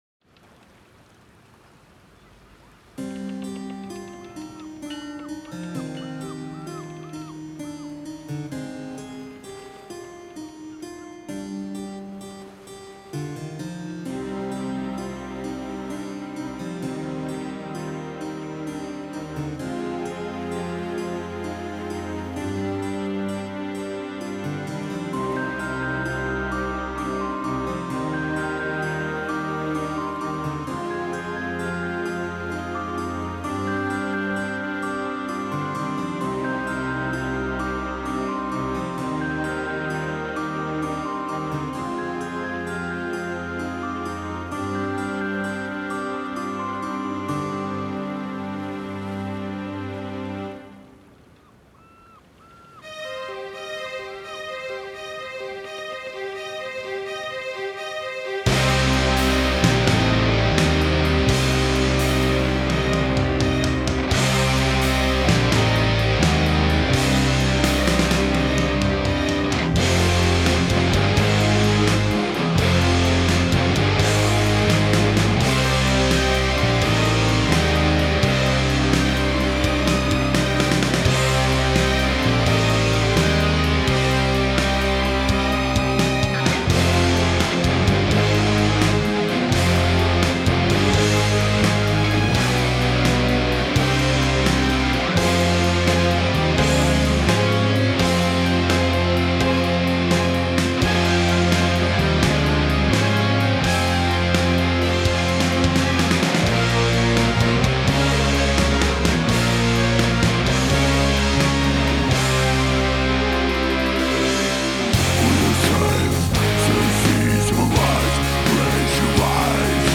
Symphonic Death Metal Format